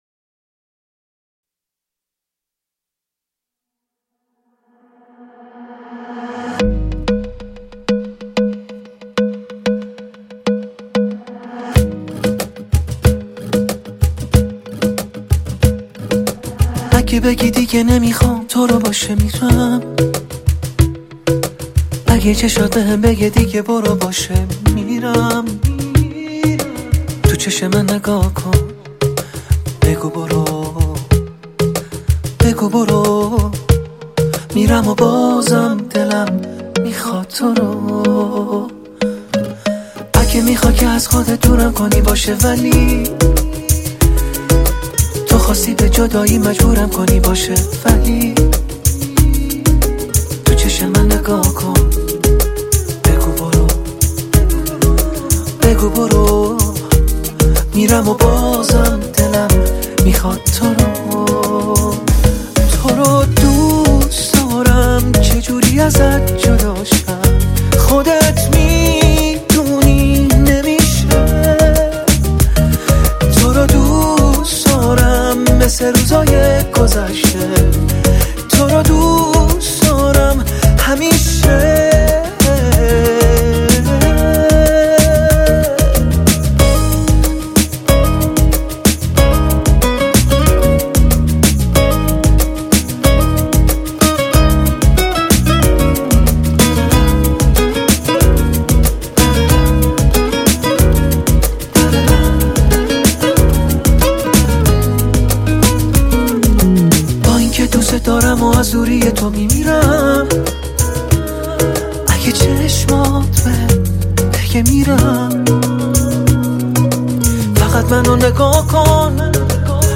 Эрон мусиқаси